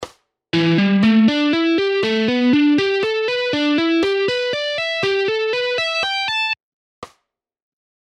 Same lick in half speed:
Half-Speed-Pentatonic-Scale-Guitar-Licks-2.mp3